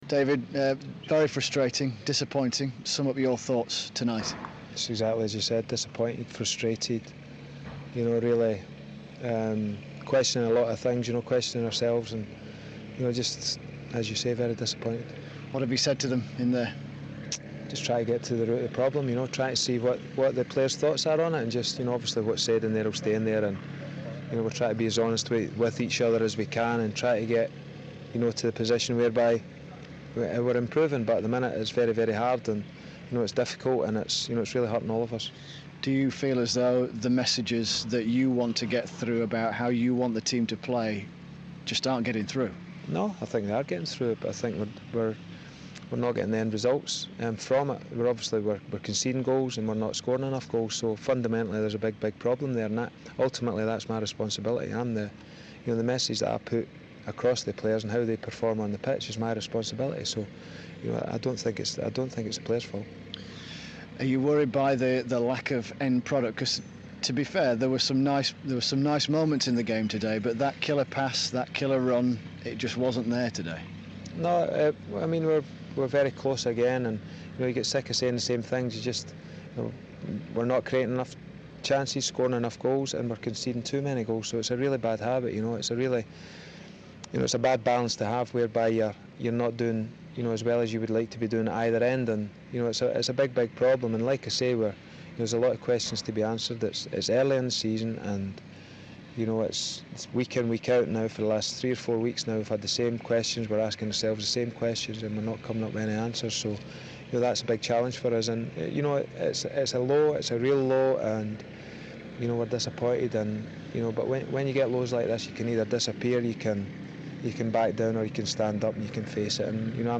David Weir interview